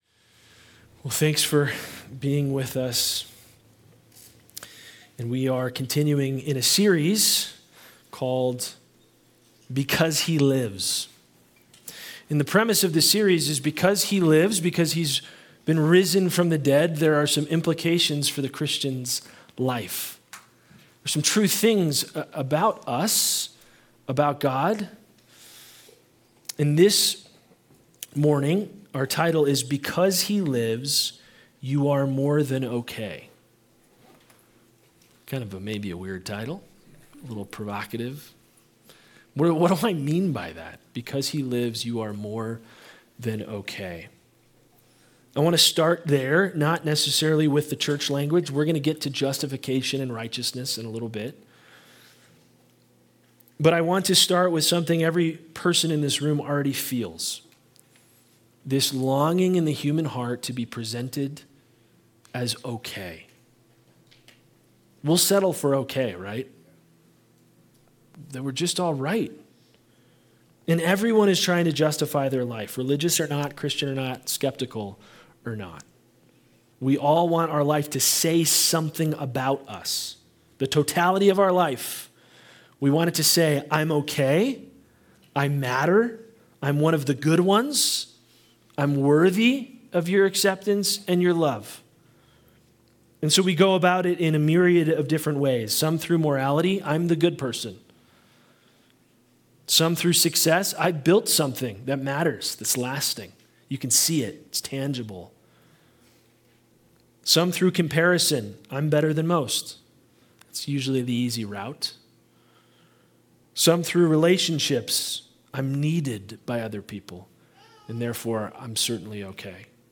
Anchor Way Sunday Service